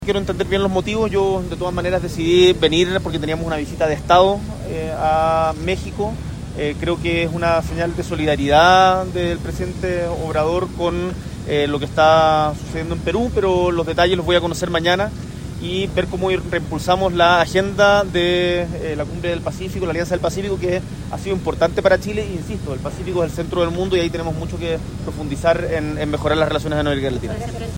Al bajar del avión, Boric se refirió a la decisión del Presidente mexicano, Andrés Manuel López Obrador (AMLO), de posponer el encuentro, ante la respuesta del Congreso de Perú de no permitir la asistencia del mandatario Pedro Castillo.
BORIC-AEROPUERTO-02.mp3